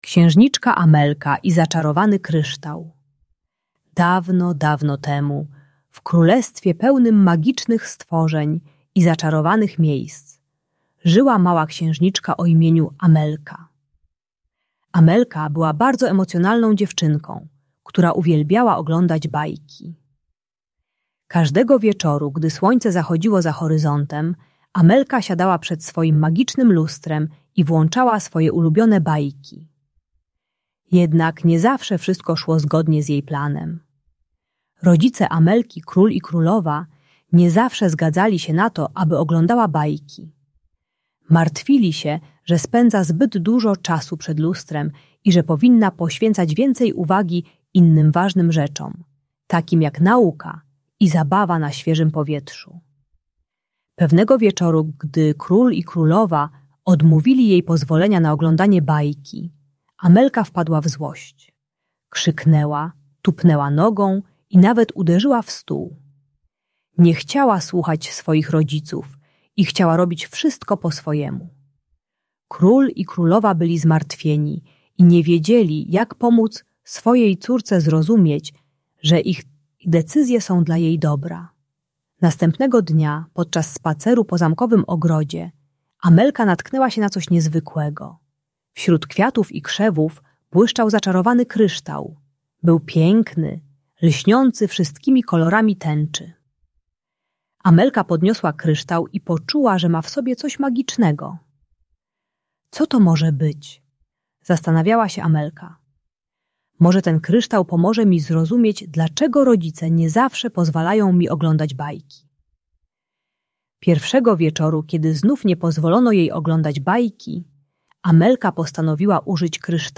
Historia księżniczki Amelki i zaczarowanego kryształu - Bunt i wybuchy złości | Audiobajka